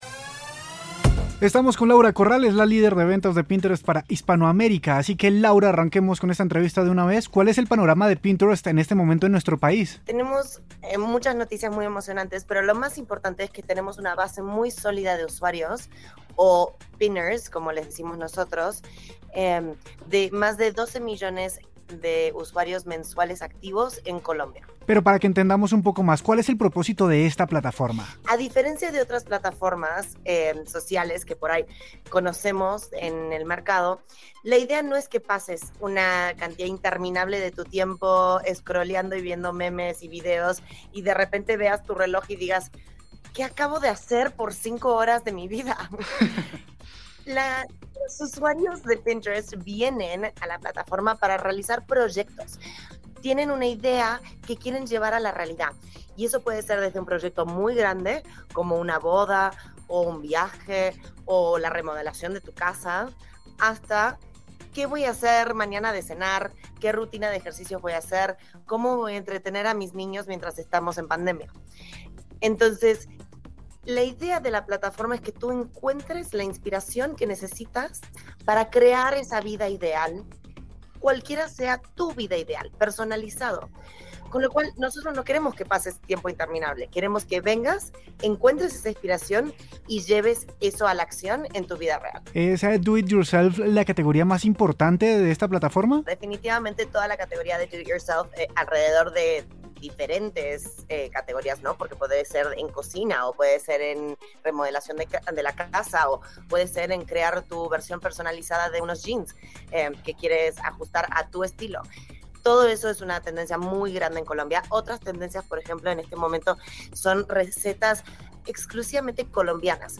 Algunas solo contienen la función de mensajería instantánea, otras te permiten compartir fotos, videos e incluso, post del día a día con duración de 24 horas.